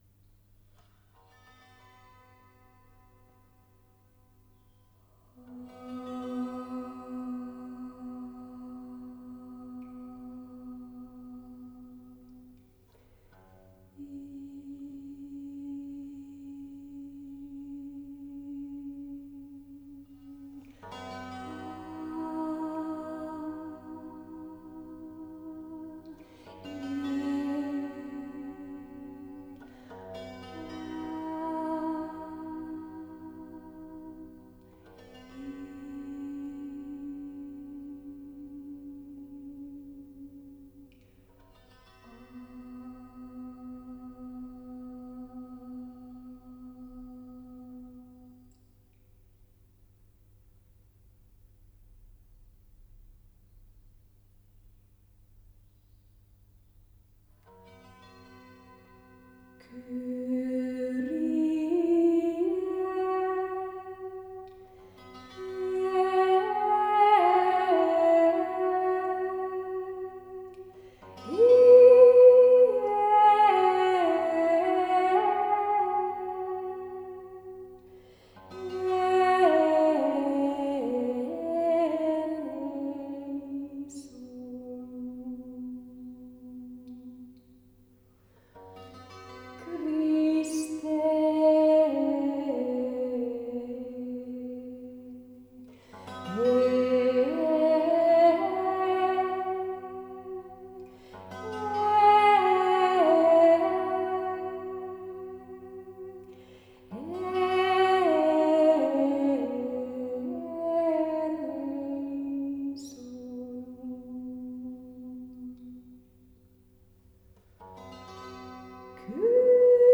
Meditativ sång med Vox Silentii